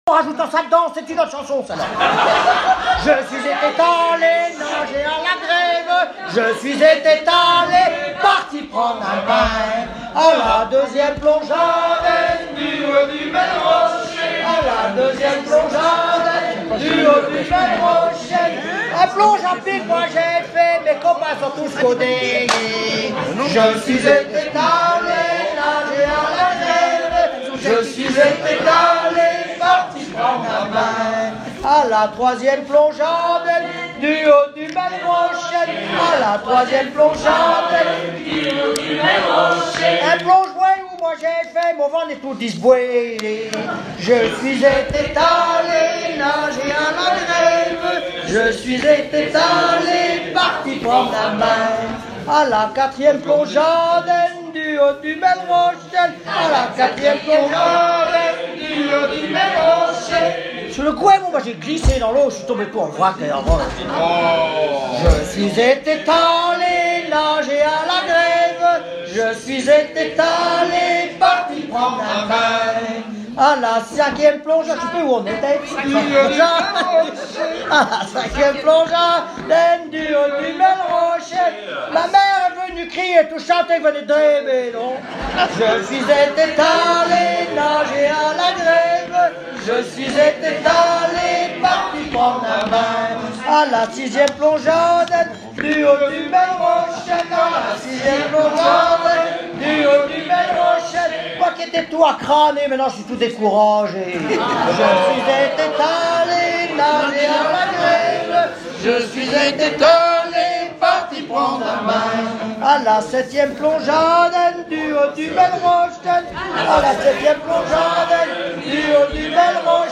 Genre énumérative
Catégorie Pièce musicale inédite